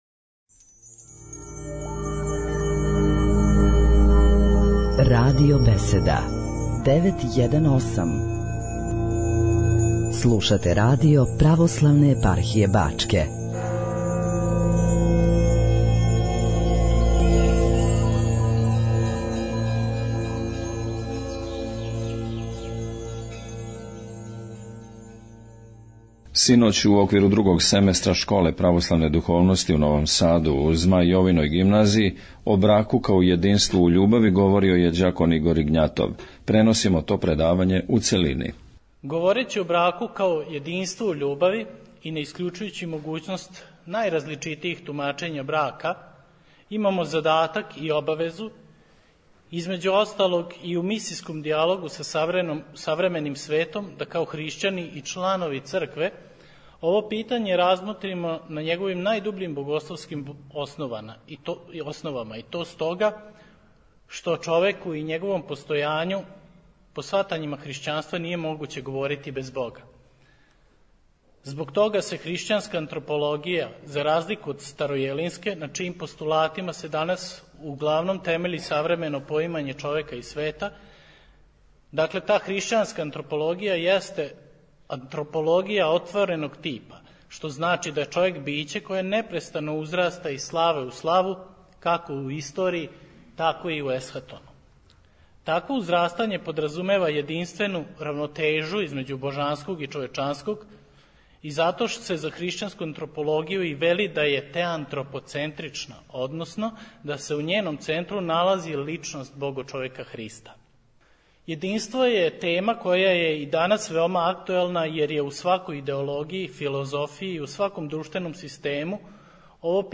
Звучни запис предавања
Нови Сад